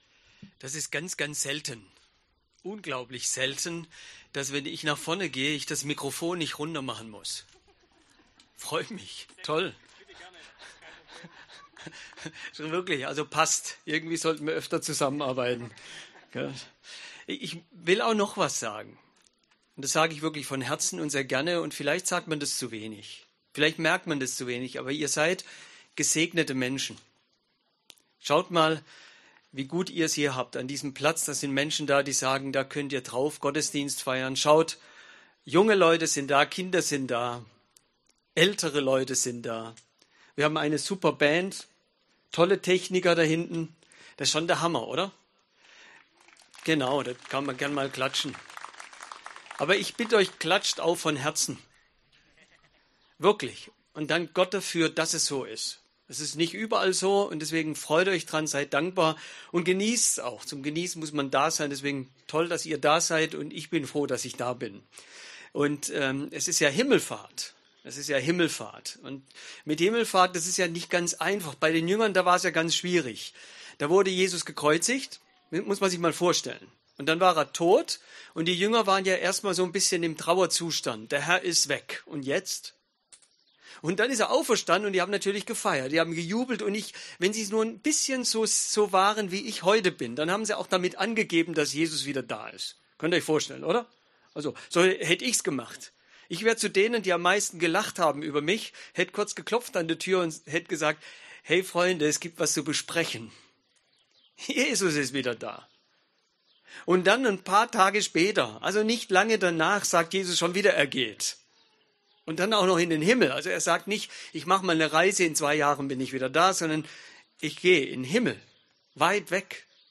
Predigt
im Himmelfahrts-Gottesdienst am Familientag.